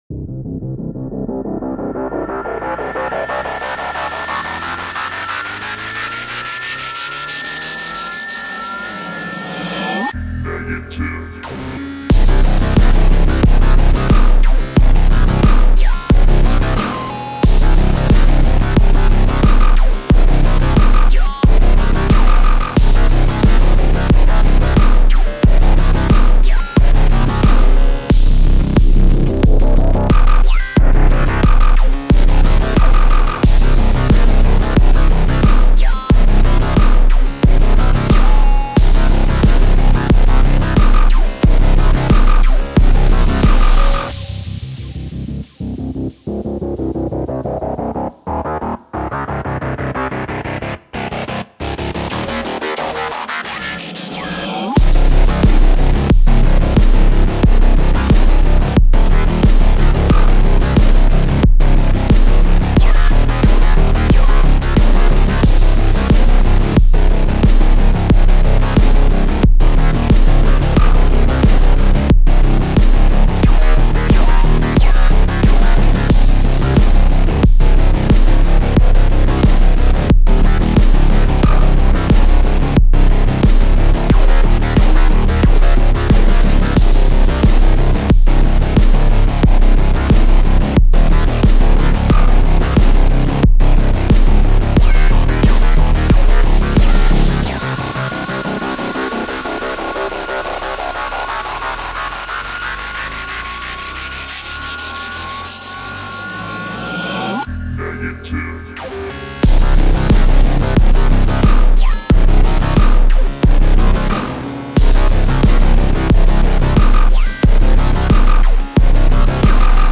Альбом: Dark Techno